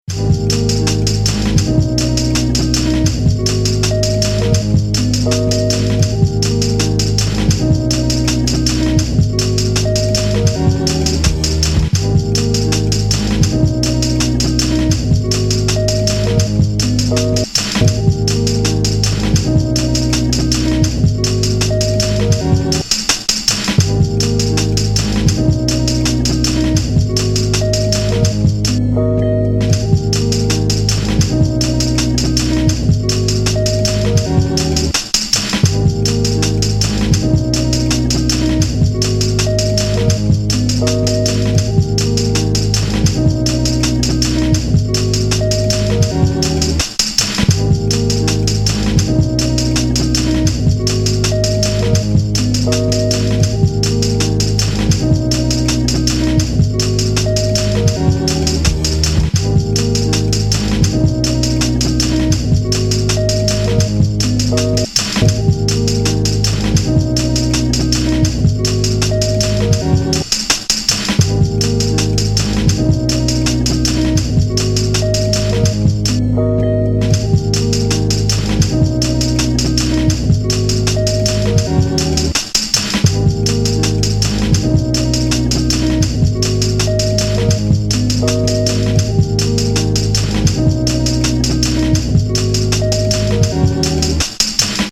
Philly producer